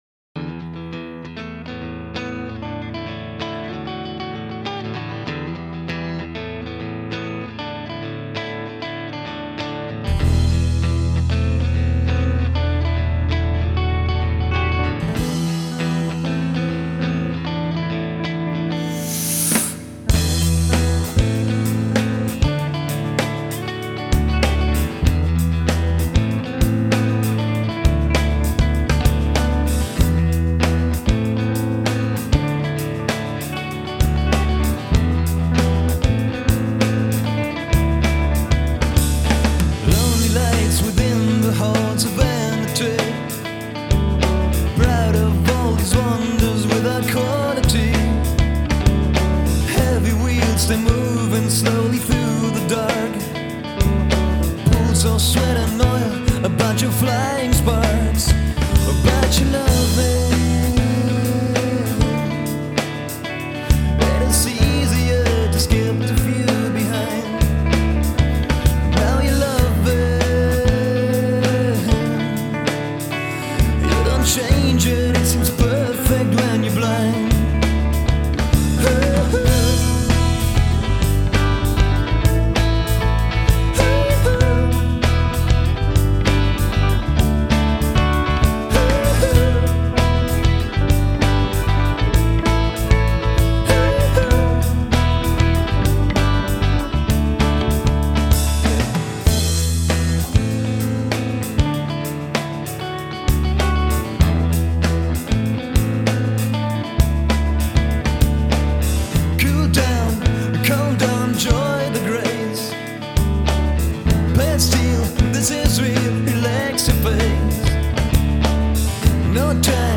Also griffen wir die Idee wieder auf mit ähnlicher Besetzung eine Band der härteren Gangart zu gründen.
Mit einem extra für diesen Zweck gekaufen 8-Spur-Minidisk-Recorder schafften wir es in einer 5-tägigen Mammut-Session alle restlichen Songs aufzunehmen, so dass wir immerhin Material für eine komplette LP zusammenhatten.